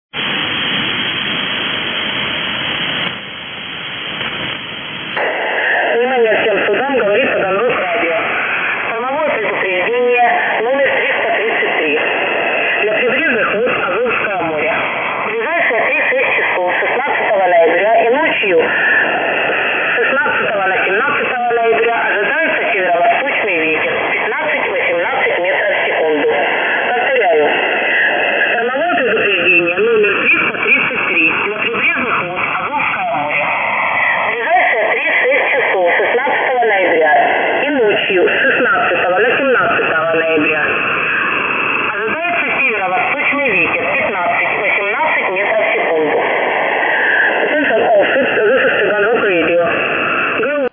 Штормовое предупреждение от Таганрог-Радио.
На частоте 2,805 Мгц штормовое предупреждение принято 16.11.2016 в 19:05 МСК.